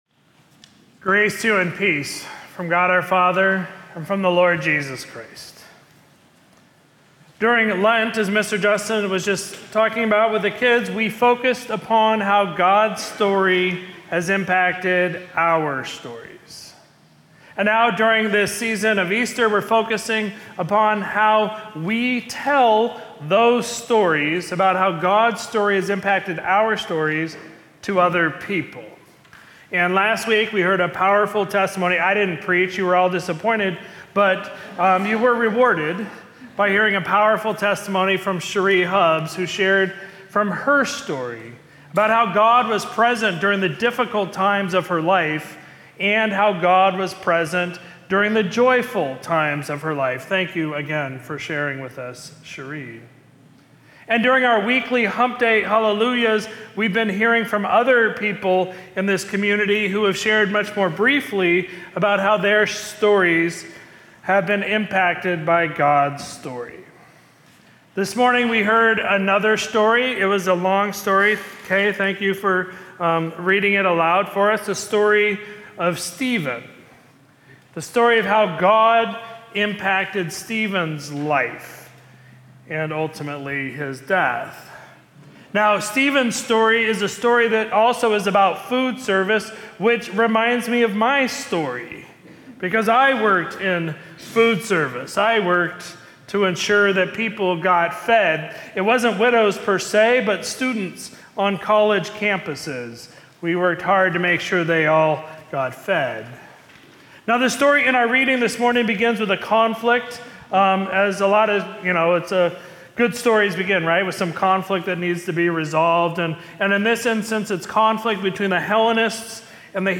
Sermon from Sunday, May 4, 2025